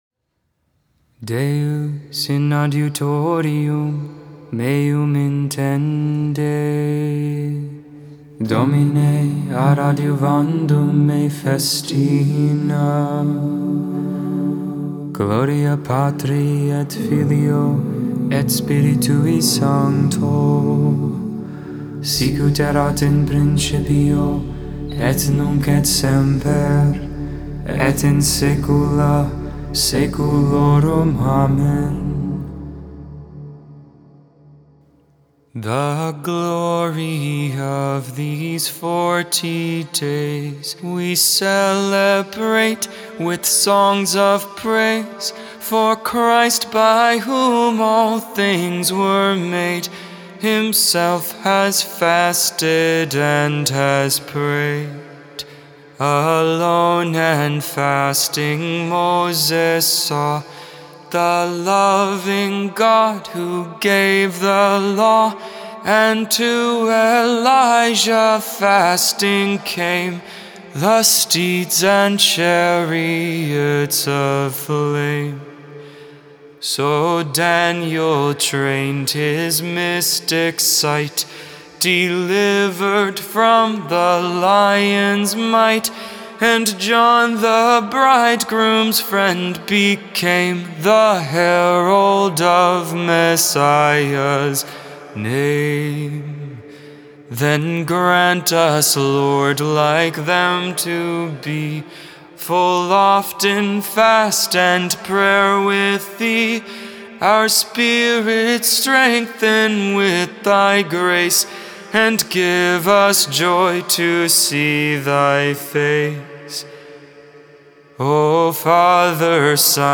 Lauds, Morning Prayer for the 1st Friday in Lent, March 11th, 2022.